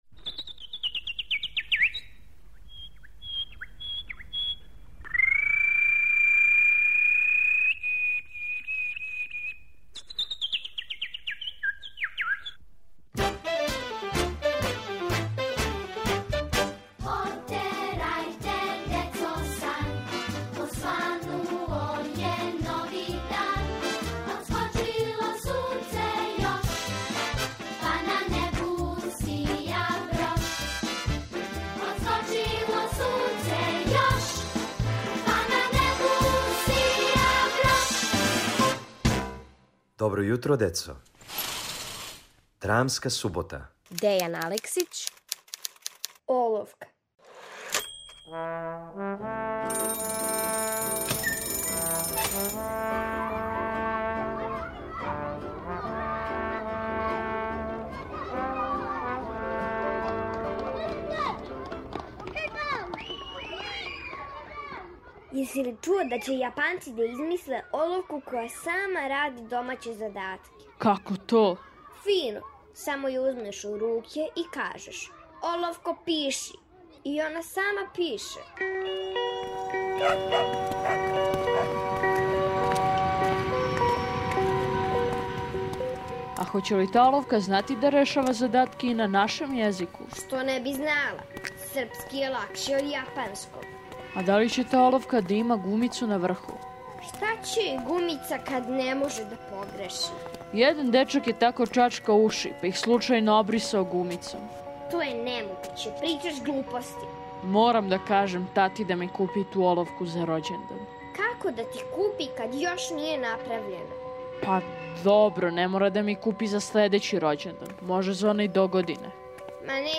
Каква је то оловка која сама решава домаће задатке? Сазнајте у краткој драми Дејана Алексића.